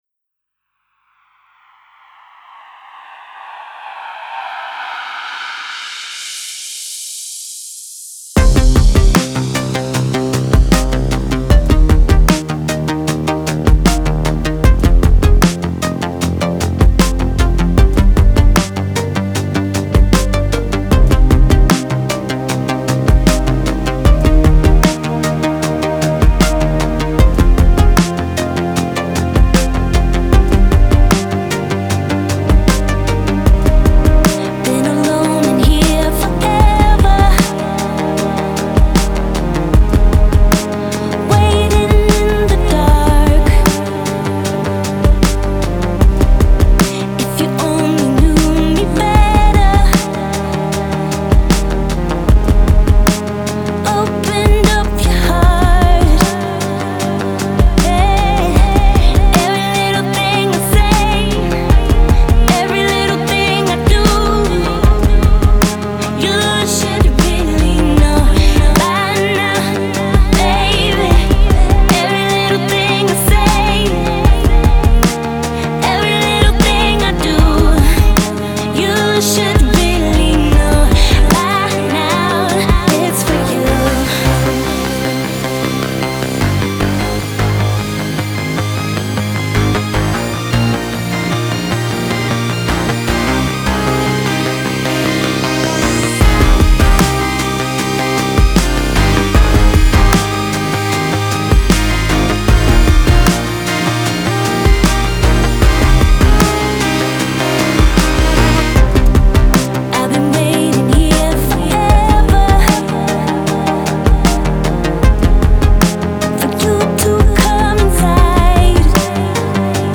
their five track synthpop adventure
” a downtempo,chill-groover,